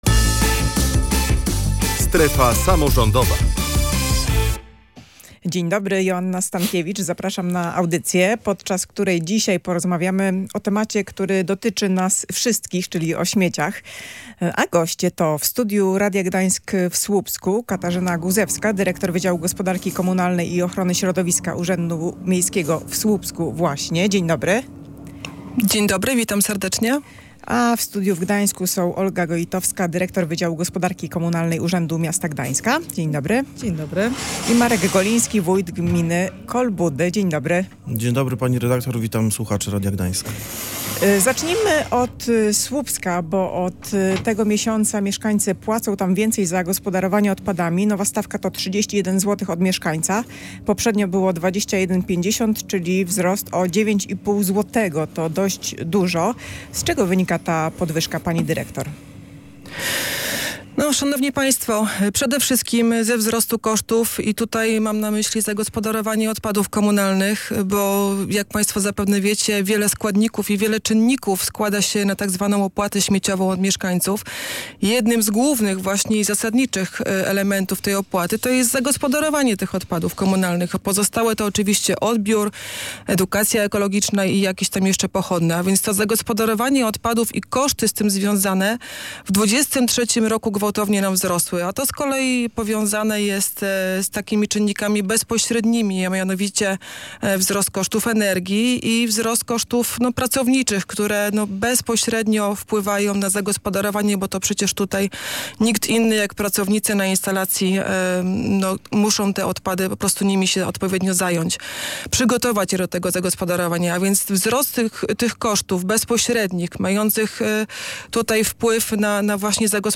Na te pytania starali się odpowiedzieć goście audycji „Strefa Samorządowa”.